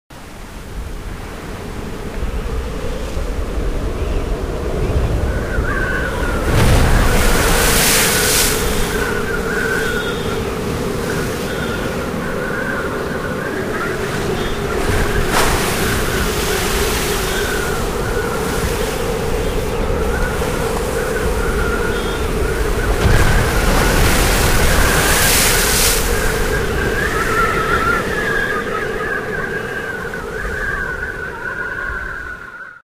Звуки шторма
Грохот мощного ветра, разбивающего волны о скалы